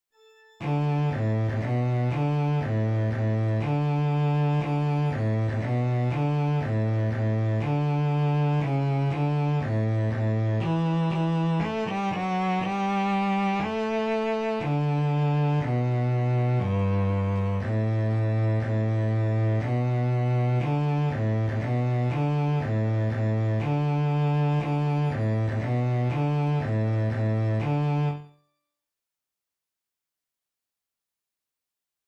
Chorproben MIDI-Files 481 midi files